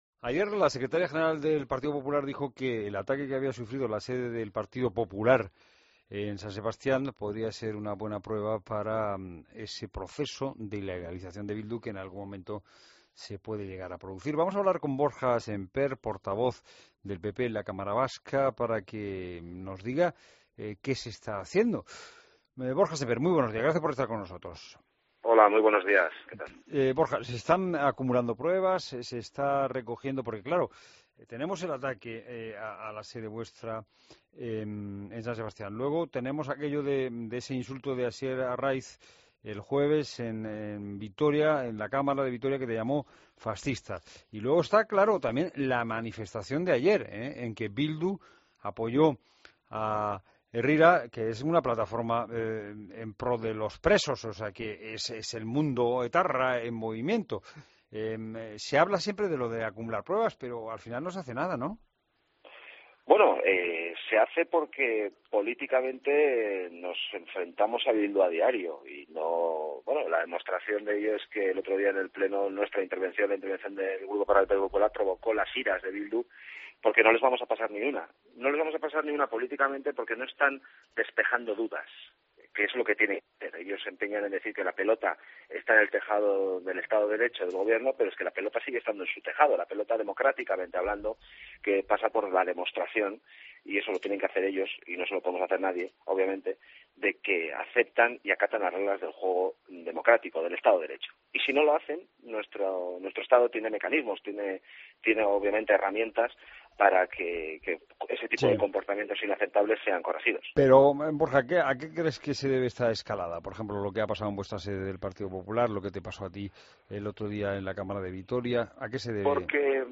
Entrevista a Borja Semper en La Mañana COPE